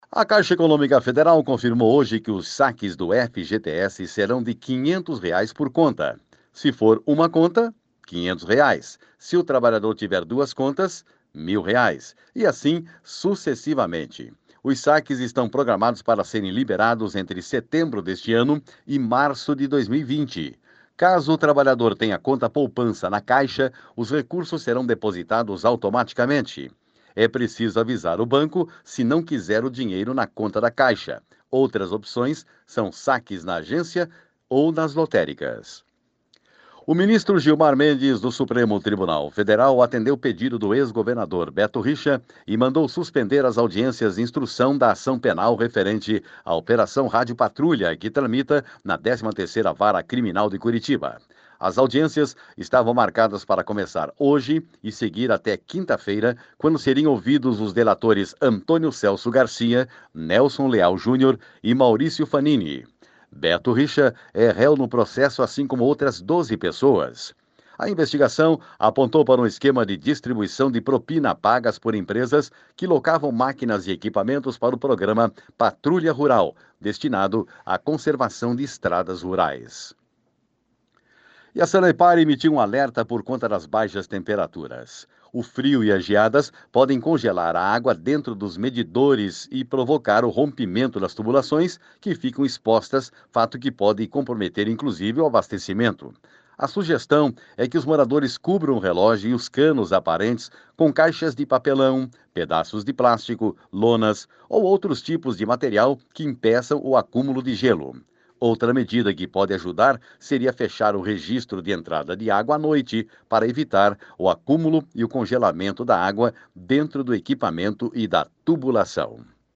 GIRO DE NOTÍCIAS SEM TRILHA